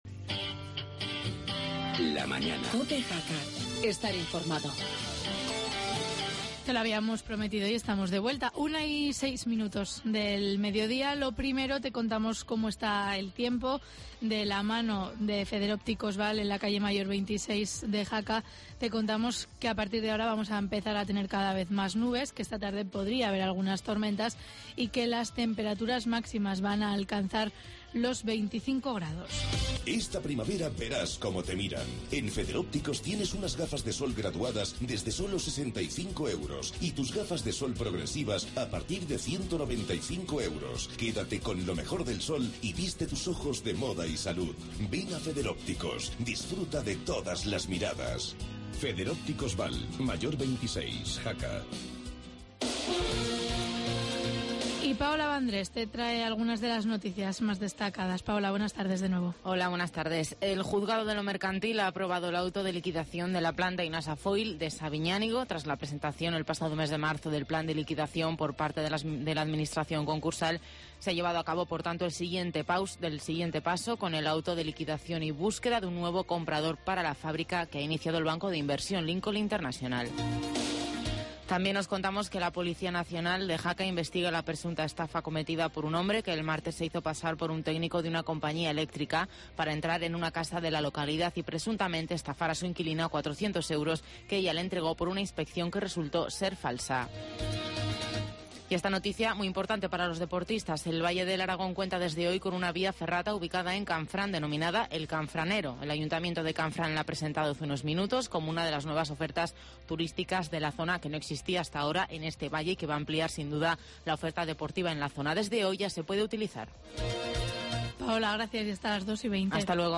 Con el alcalde de Jaca, Víctor Barrio, hablamos del nuevo Festival Folclórico de los Pirineos.